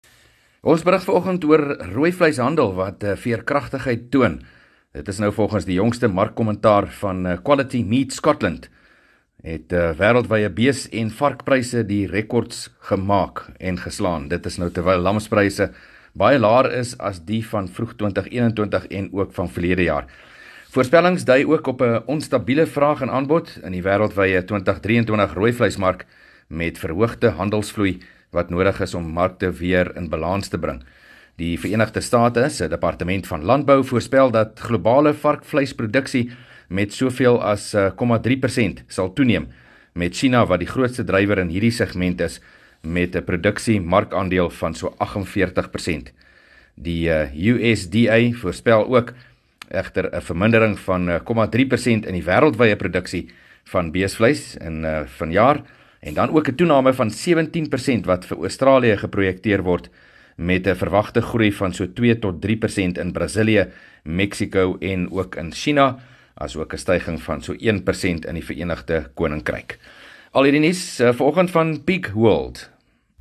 5 Jun PM berig oor wêreldwye rooivleishandel wat toon dat bees- en varkvleispryse gestyg het